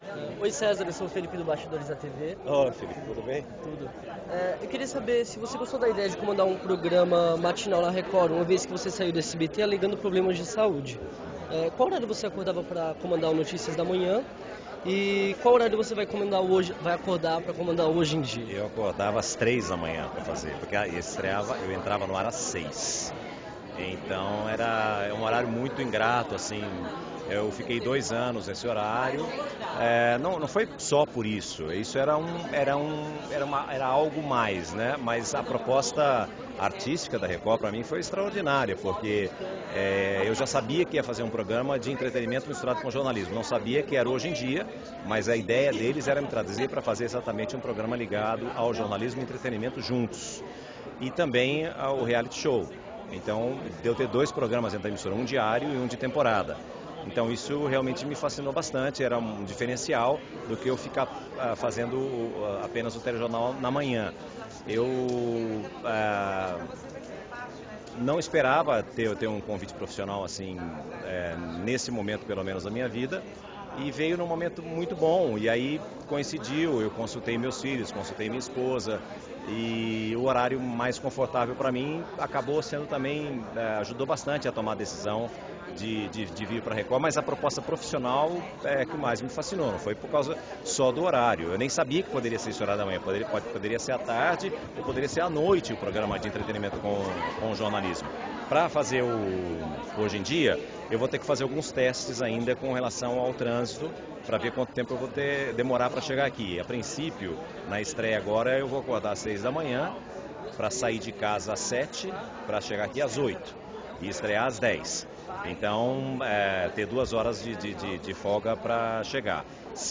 Na última quinta-feira (08/01), o site Bastidores da TV esteve presente na coletiva de imprensa do novo “Hoje em Dia”, na sede da Rede Record, na Barra Funda, em São Paulo.
Entrevista com o apresentador Cesar Filho: (Confira o áudio em boa qualidade no player abaixo)